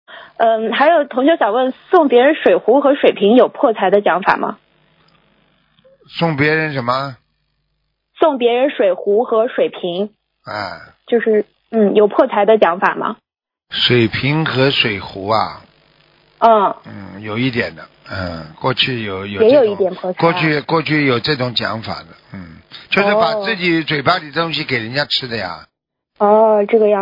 女听众同修问，送别人水壶和水瓶有破财的讲法吗？